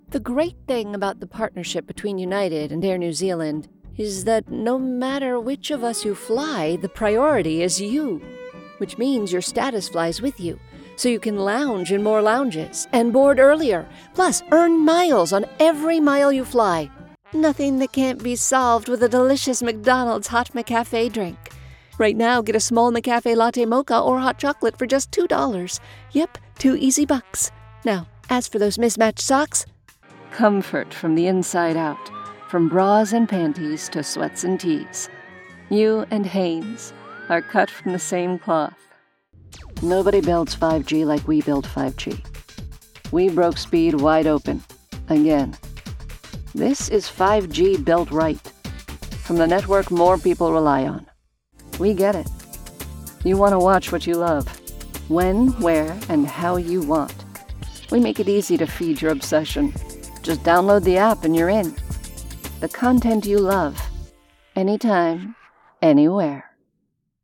Commercial VO Reel
commercial-demo-2510.mp3